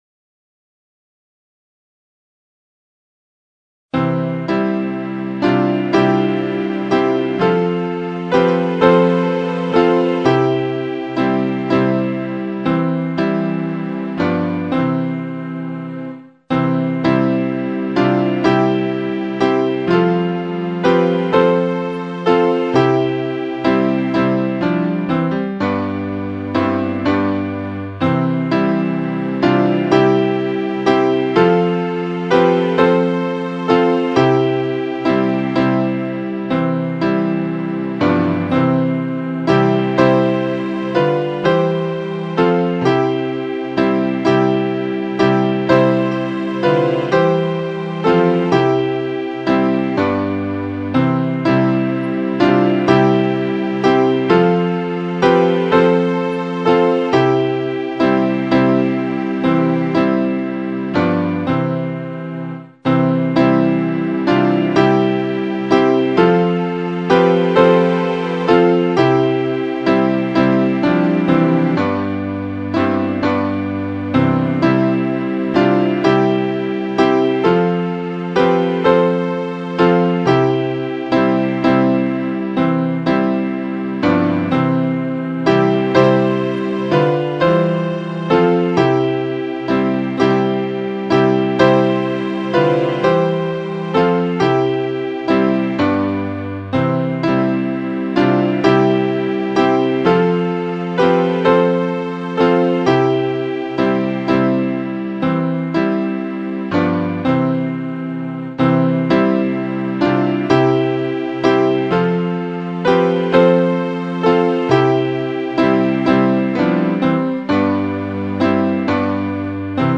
聖歌 254 楽しき祈りよ 賛美歌 310 静けき祈りの※ハ長調のまま 新聖歌 190 静けき祈りの